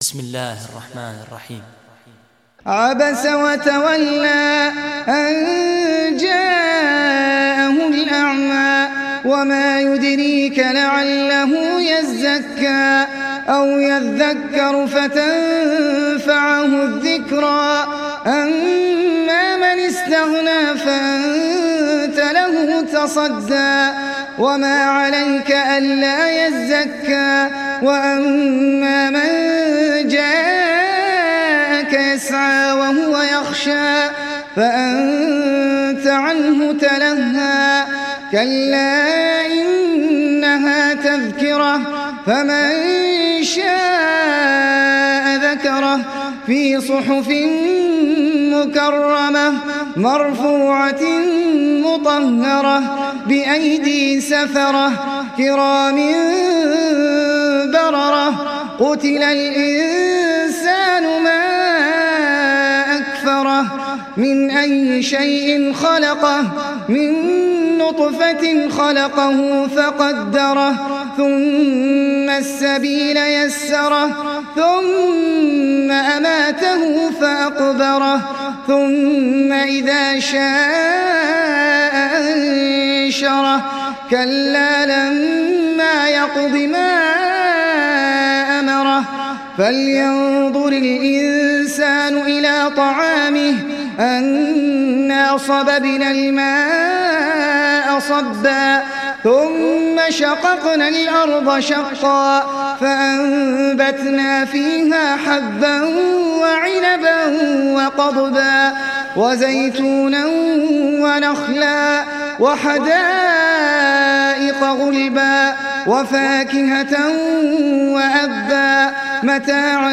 تحميل سورة عبس mp3 بصوت أحمد العجمي برواية حفص عن عاصم, تحميل استماع القرآن الكريم على الجوال mp3 كاملا بروابط مباشرة وسريعة